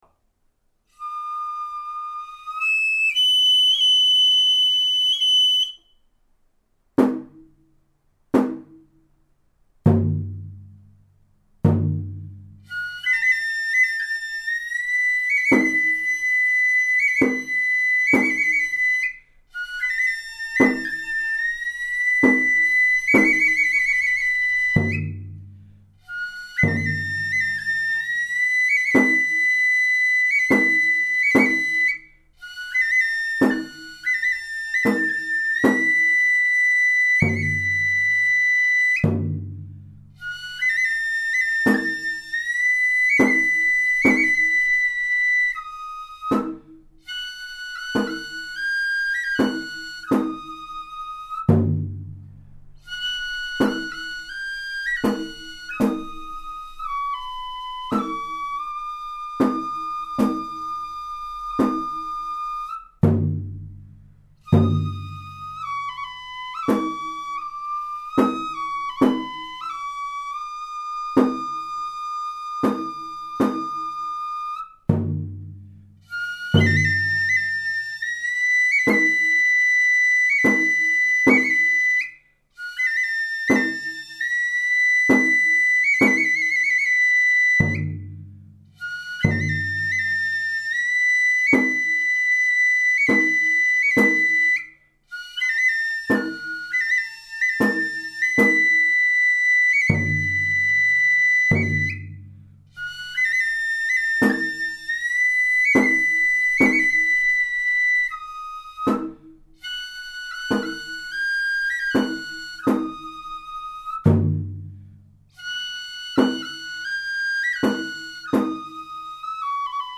なお、笛の方はとっても上手ですが、太鼓（実は大部分が私）は数箇所間違っております。
吹き出しの高音や太鼓の入りは、知立でよく使われているパターンでやっています。